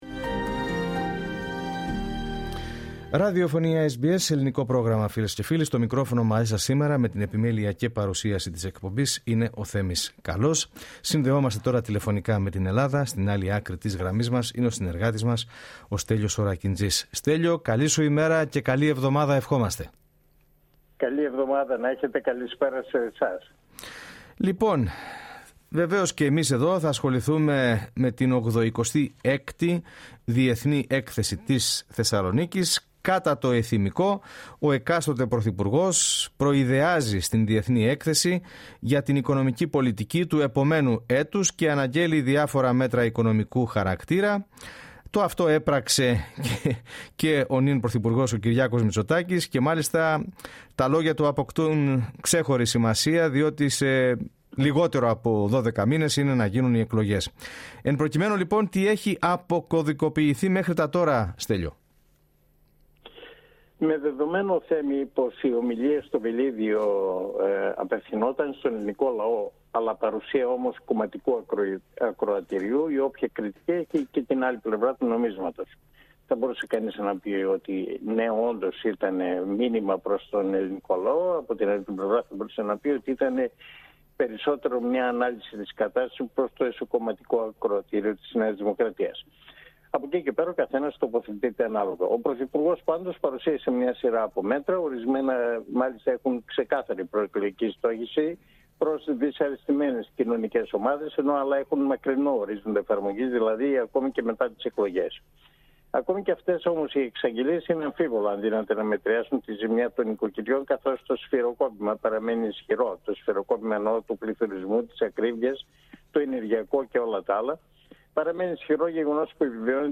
ανταποκριση-απο-ελλαδα-12-σεπτεμβριου.mp3